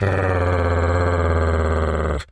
wolf_growl_01.wav